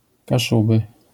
Kashubia or Cassubia (Kashubian: Kaszëbë or Kaszëbskô; Polish: Kaszuby [kaˈʂubɨ]